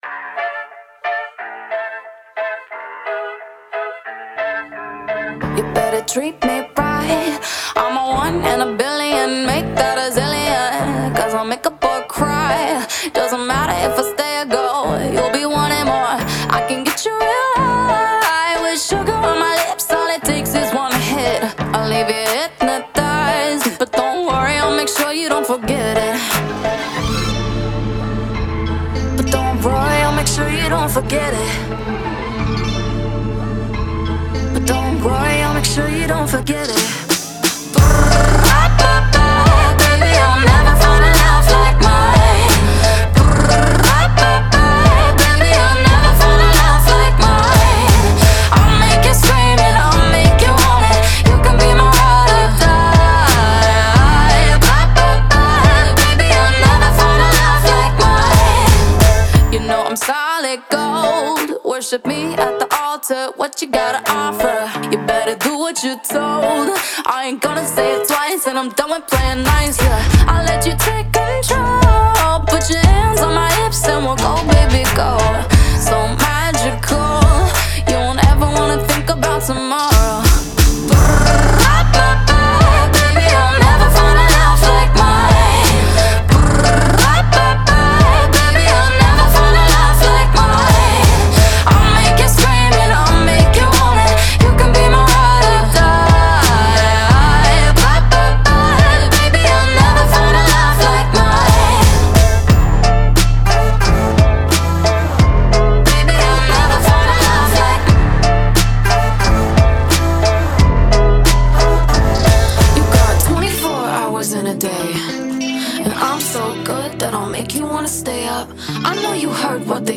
BPM89-89
Audio QualityPerfect (High Quality)
Pop Song for StepMania, ITGmania, Project Outfox
Full Length Song (not arcade length cut)